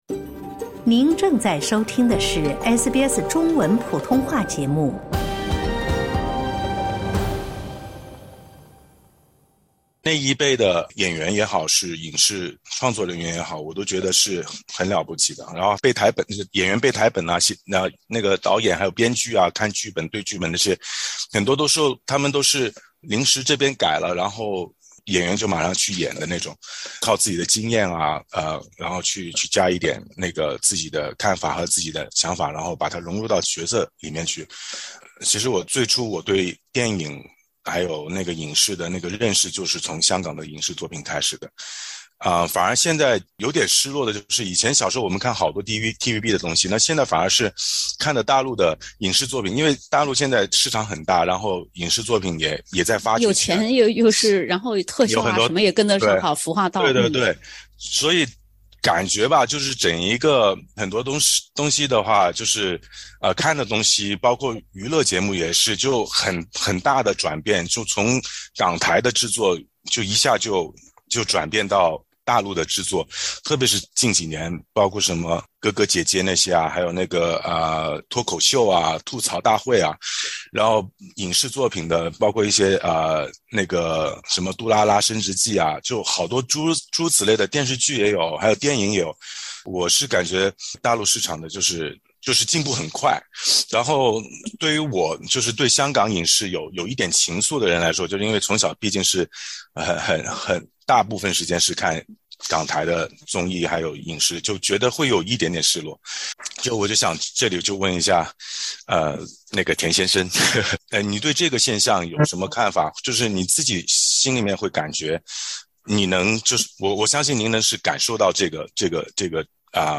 SBS全新谈话类节目《对话后浪》，倾听普通人的烦恼，了解普通人的欢乐，走进普通人的生活。